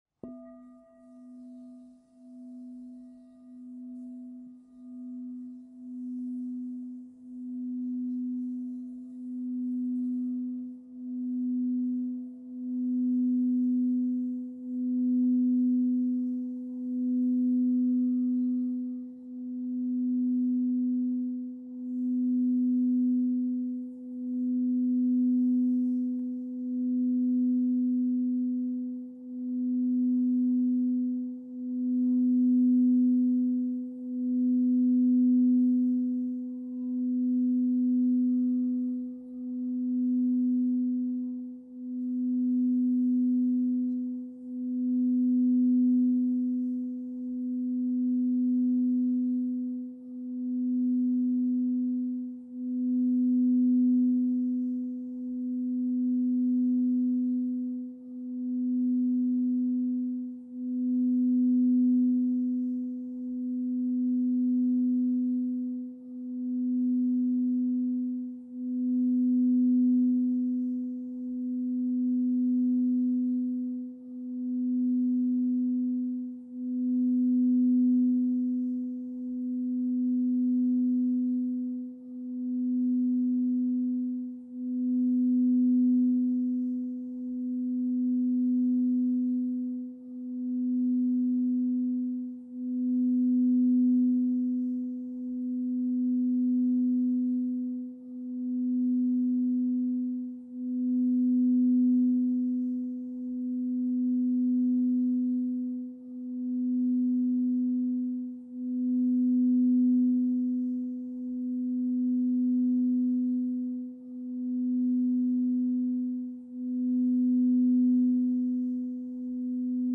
These tracks can be downloaded for free and contain the sound of Alchemy crystal singing bowls. These special bowls are clear quartz with other crystals and metals fused to the surface in a patented process by Crystal Tones™.
Each track features one bowl being played for about 10 minutes.
Track 1: Amethyst/Citrine double alchemy bowl (2 layers are fused to the clear quartz bowl)